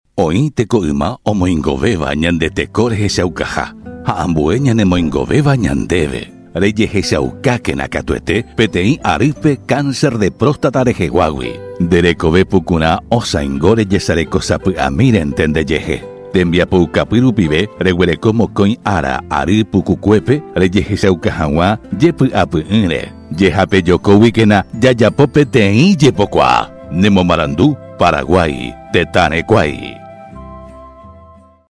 SPOT RADIAL